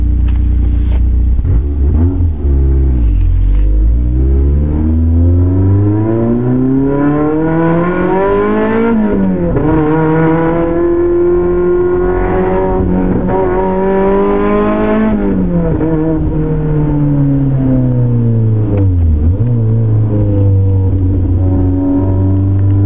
It might just be the noise of the Blacktop, but i think its the best 1.6 N/A ever.
MK1 MR2 with a Black top taking it up to 6000rpm, it redlines at ~8.3k. Tell me that didnt bring impure thoughts to mind.